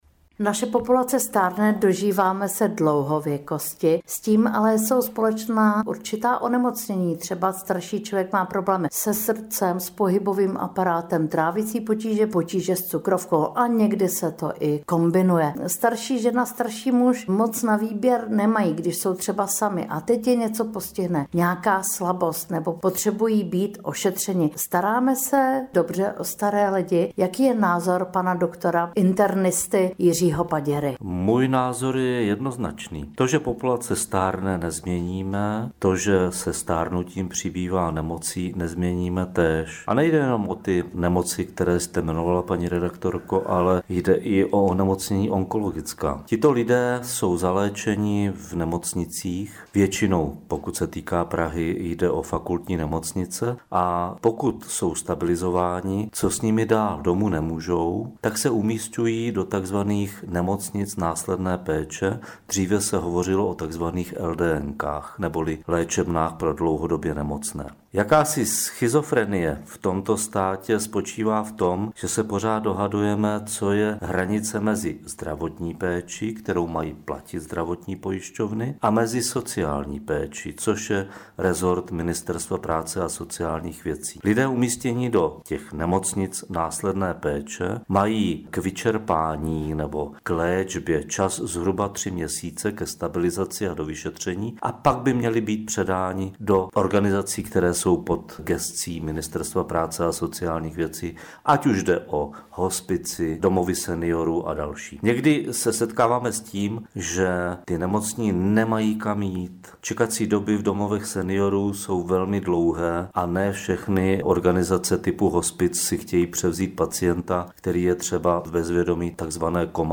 AUDIO rozhovor: Jaká je současná situace v péči a léčbě dlouhodobě nemocných?